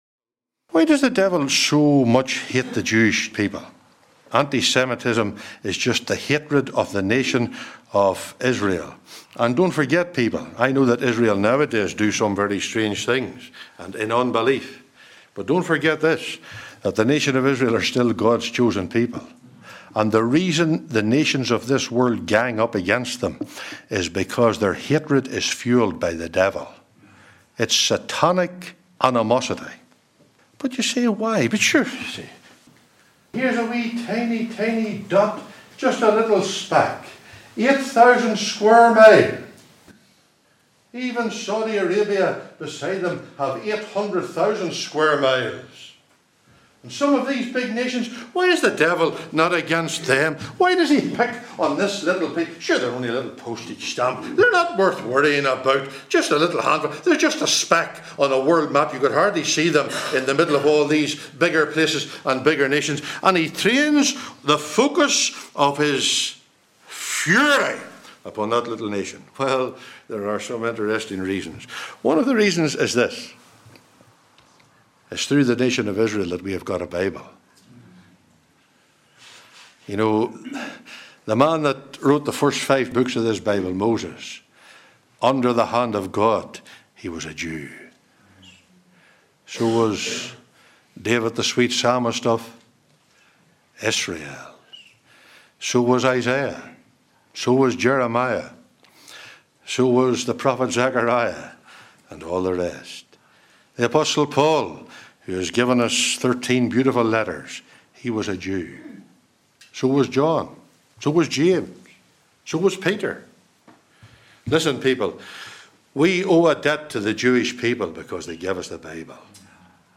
(From a recording made in Northern Ireland in 2018)
Sermons of Special Interest